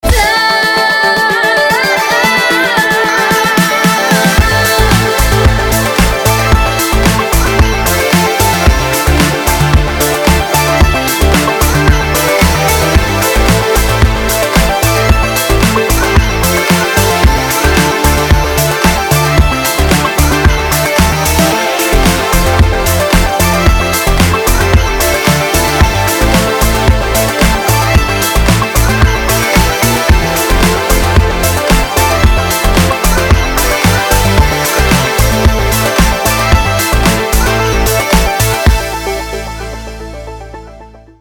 Вот я подзажал. Только много чего, кроме лимитера юзал.